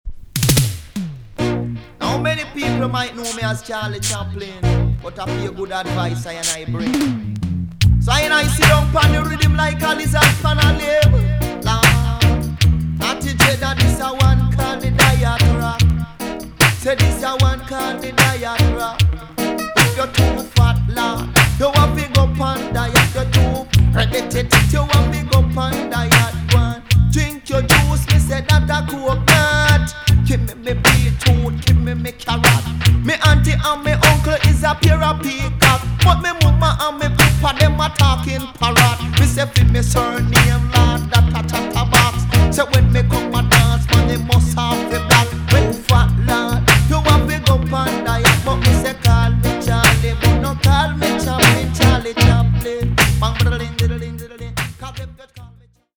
TOP >DISCO45 >80'S 90'S DANCEHALL
EX- 音はキレイです。